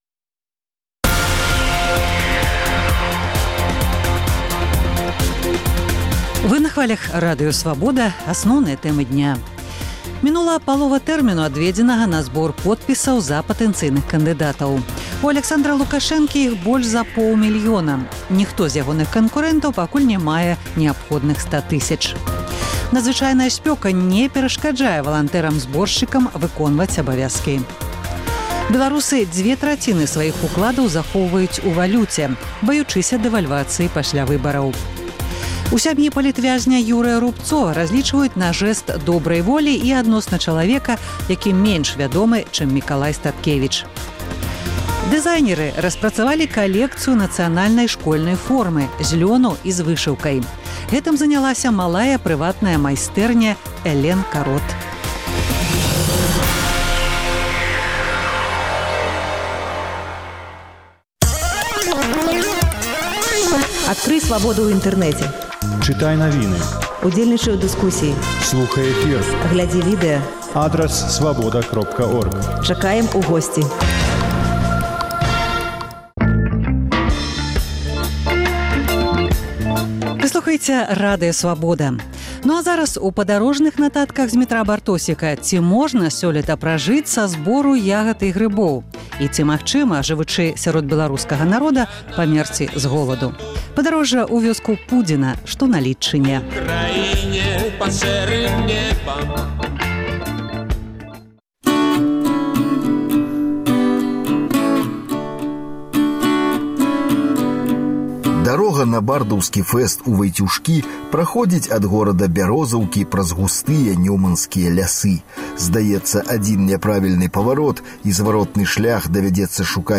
Ці можна пражыць у Беларусі, зьбіраючы ягады і грыбы? Паездкі нашых карэспандэнтаў па гарадах і вёсках Беларусі. Чарговая перадача - зь вёскі Пудзіна на Лідчыне.